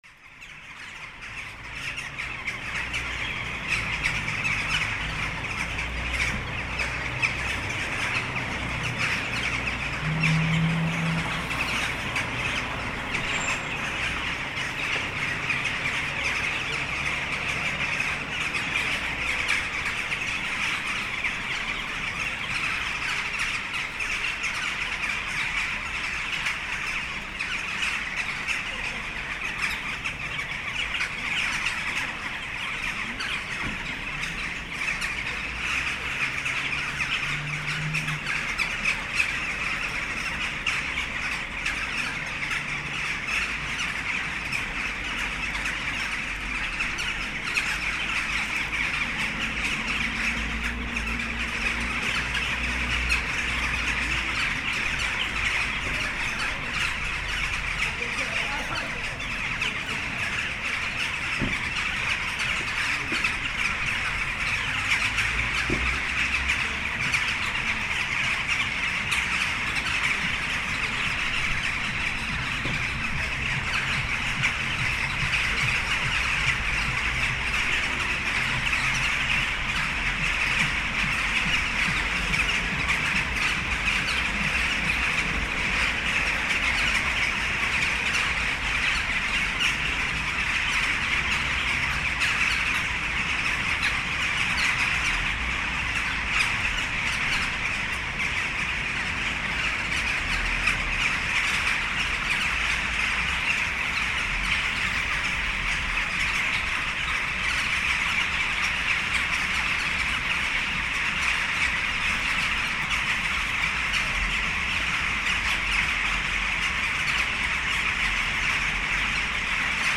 Bird sounds at Linkoping